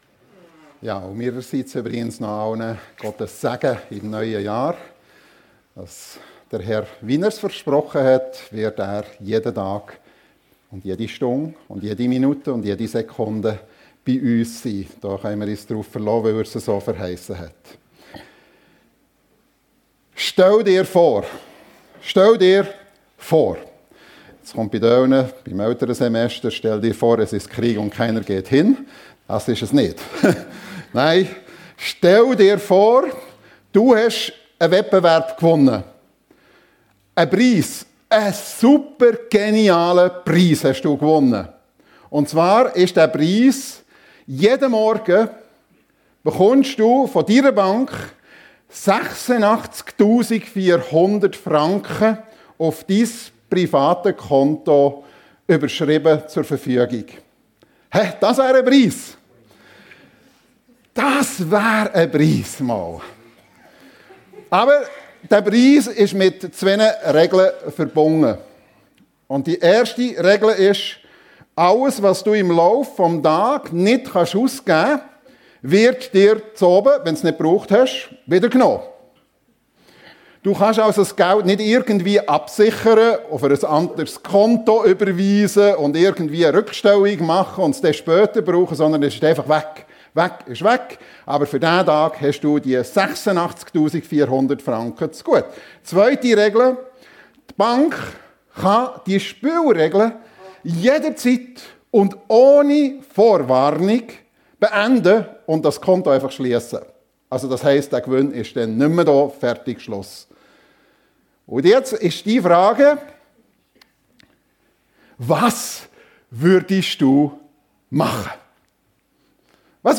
Nutze und geniesse deine Lebenszeit ~ FEG Sumiswald - Predigten Podcast